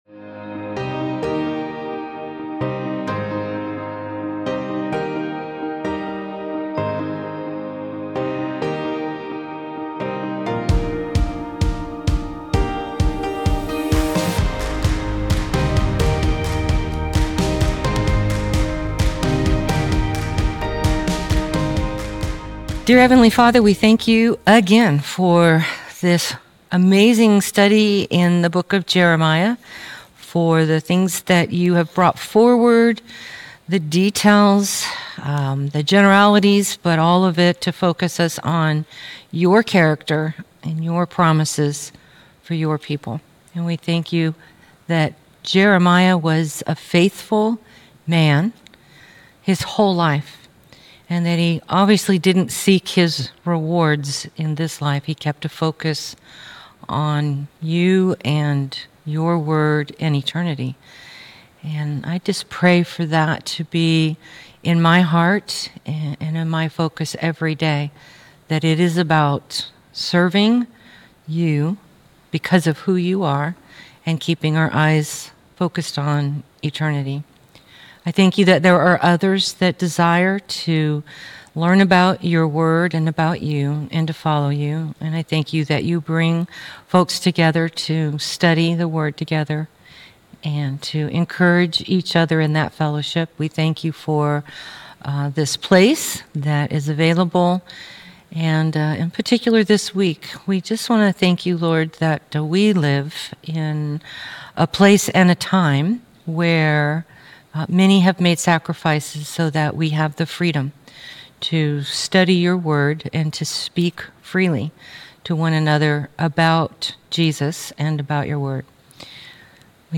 Jeremiah - Lesson 51C | Verse By Verse Ministry International